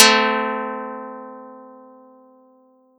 Audacity_pluck_3_13.wav